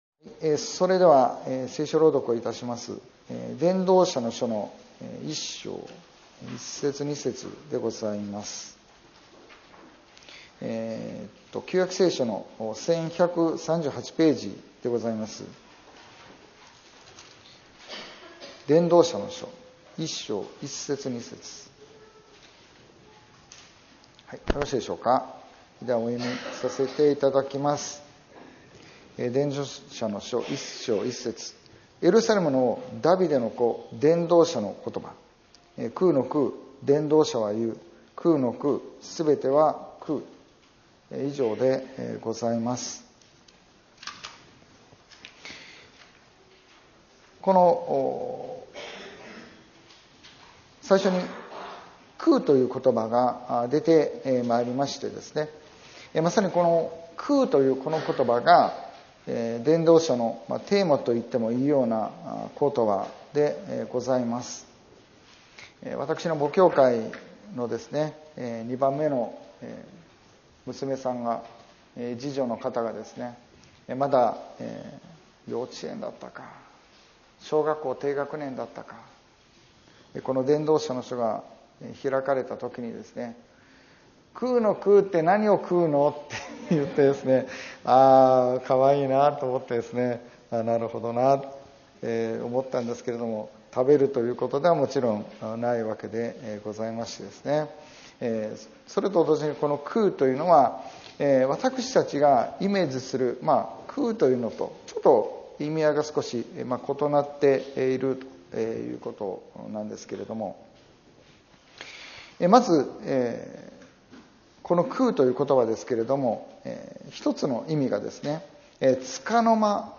2025年4月27日礼拝説教「空なる人生からの転換」